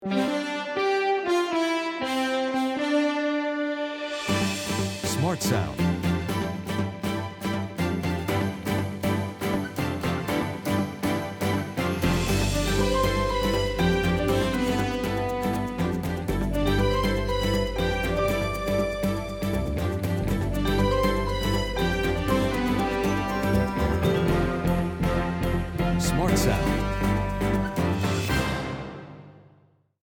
Will you listen to several short pieces of background music that we are considering using in a 30 second trailer/advert for the pilot programme?